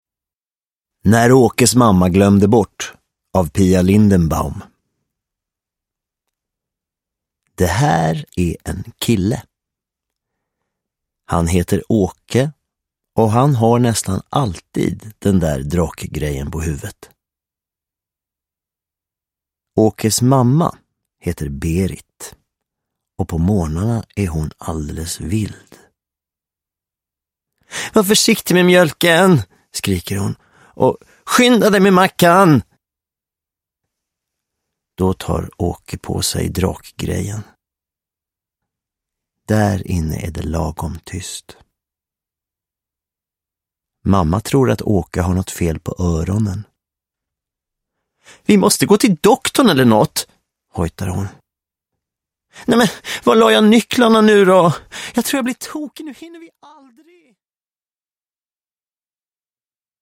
När Åkes mamma glömde bort – Ljudbok – Laddas ner
Uppläsare: Björn Kjellman